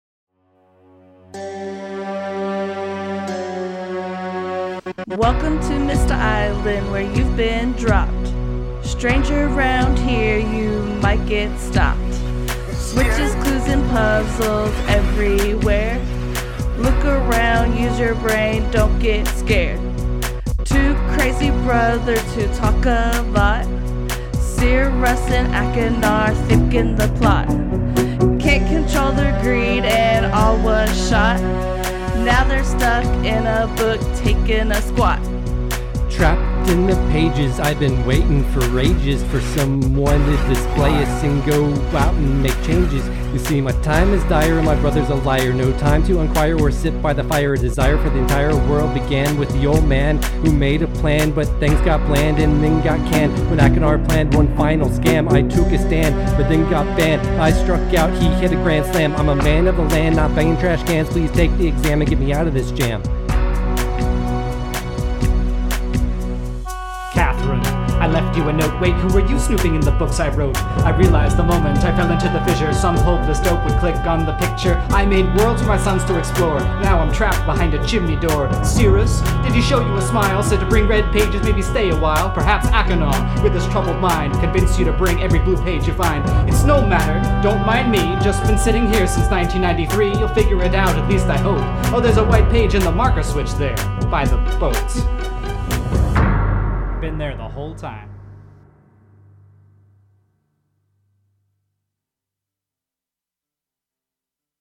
Rap from Episode 23: Myst – Press any Button